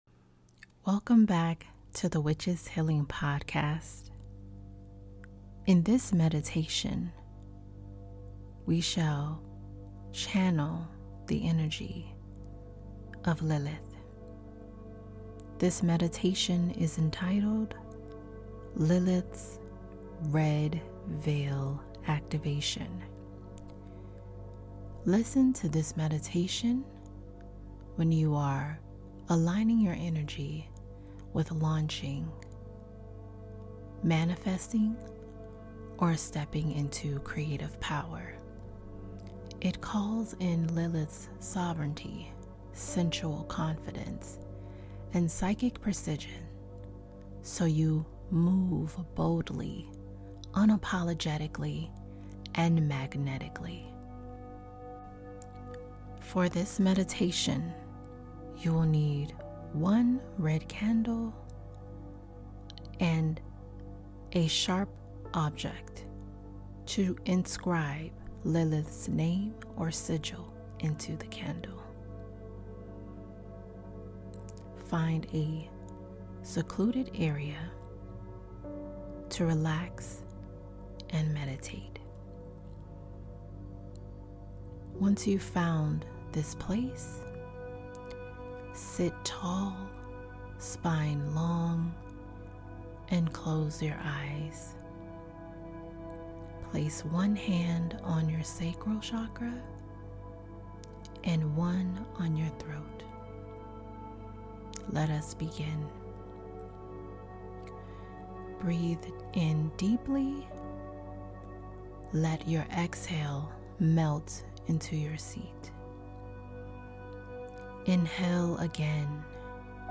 This guided meditation helps you align with Lilith’s energy to step into your next level of visibility and impact—unapologetically.
✨Includes ambient ethereal music + poetic invocation